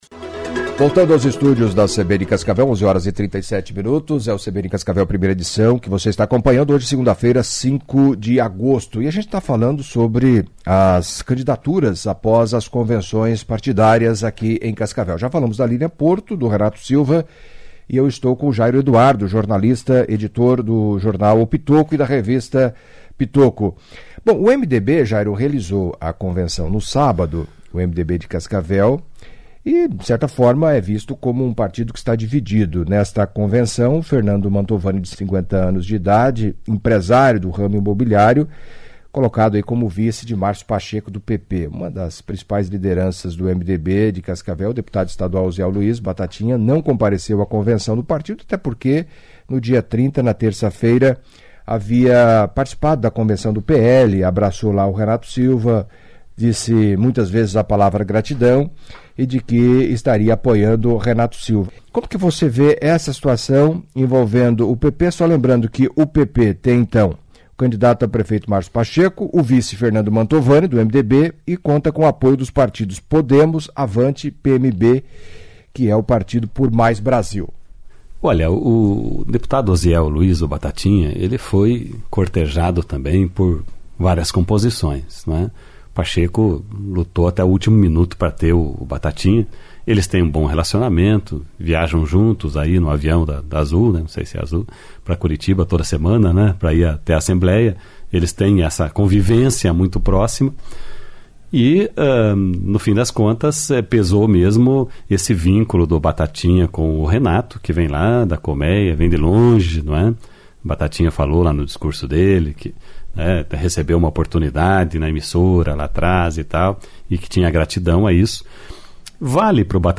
Após as convenções e definido que Cascavel terá quatro candidatos a prefeito nas eleições de outubro, a CBN abre espaço para jornalistas de editoria política de jornais, colunas e blogs da cidade para uma análise sobre o atual cenário e uma projeção do que pode acontecer durante a campanha e no pleito marcado para o dia 06 de outubro. Os candidatos são: Renato Silva (PL), Edgar Bueno (PSDB), Márcio Pacheco (PP) e Professora Liliam (PT).